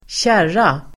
Uttal: [²tj'är:a]